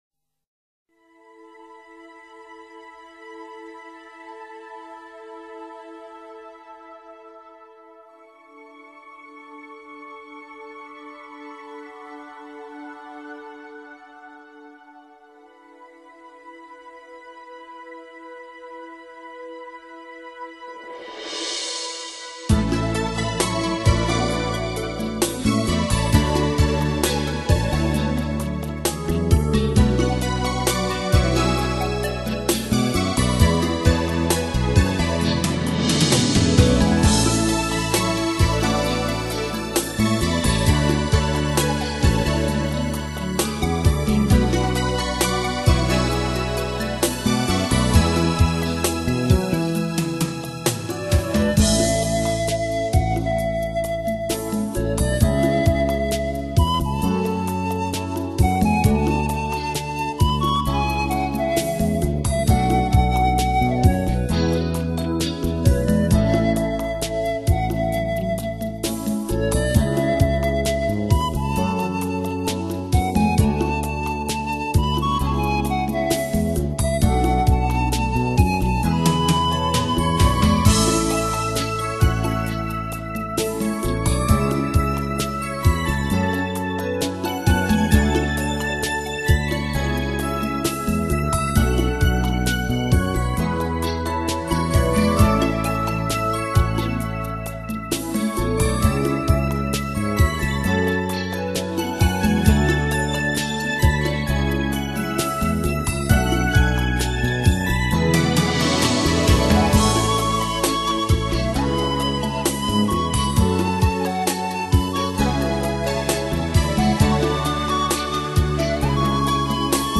这是一张具有治疗功效的减压音乐专辑,
你的神经将得到最大限度的放松,